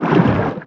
File:Sfx creature pinnacarid push 04.ogg - Subnautica Wiki
Sfx_creature_pinnacarid_push_04.ogg